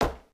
sounds / material / human / step / t_wood3.ogg
t_wood3.ogg